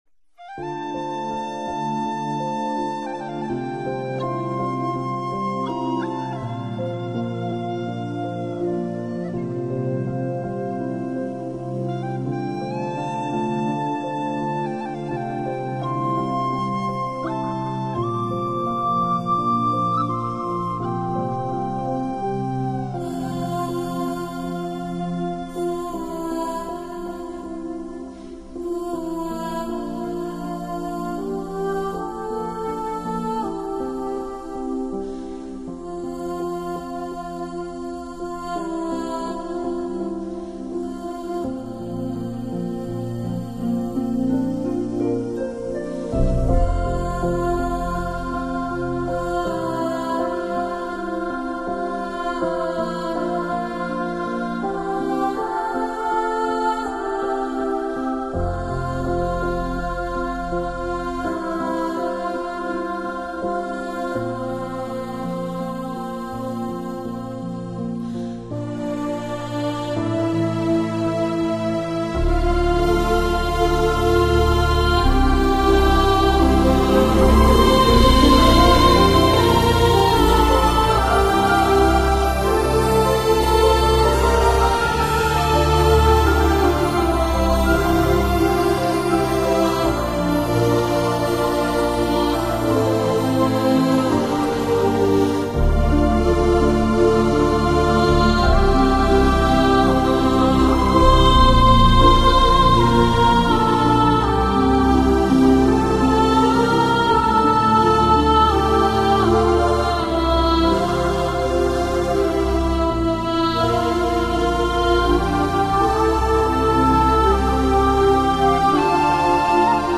موسیقی عاشقانه ، احساساتی و نوستالژیک